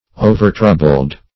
Overtroubled \O`ver*trou"bled\, a. Excessively troubled.